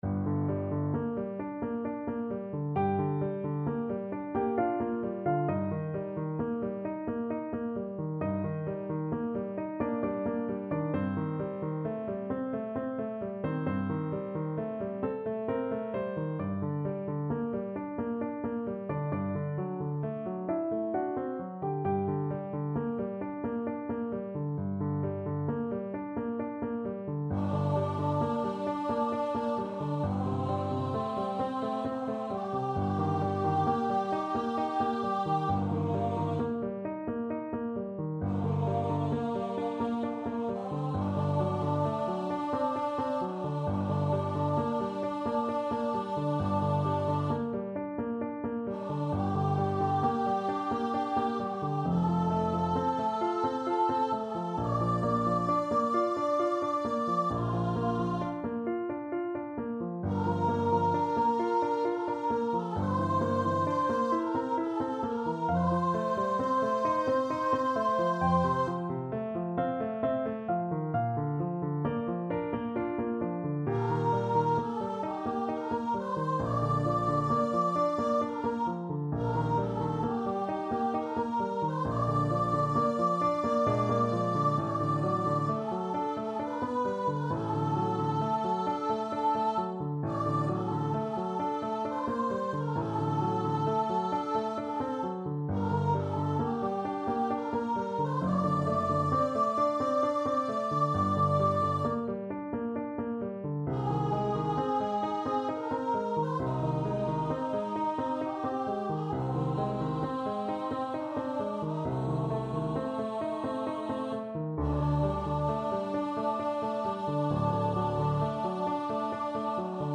~ = 100 =66 Andante
3/4 (View more 3/4 Music)
Classical (View more Classical Voice Music)